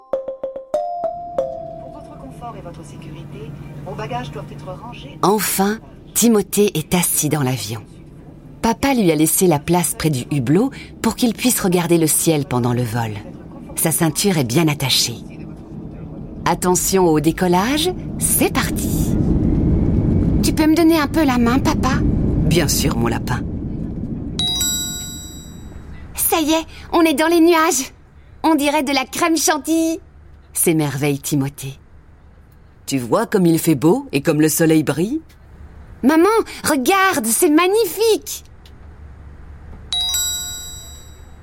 Un livre audio pour familiariser les petits à cette première expérience.